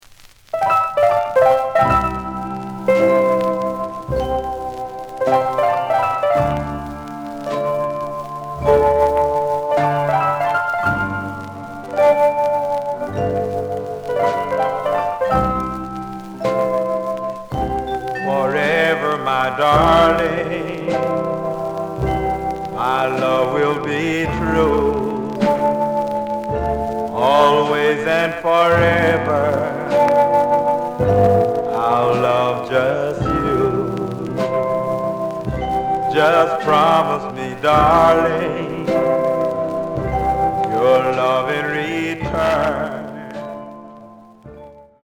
試聴は実際のレコードから録音しています。
●Genre: Rhythm And Blues / Rock 'n' Roll
●Record Grading: VG (両面のラベルにダメージ。盤に若干の歪み。プレイOK。)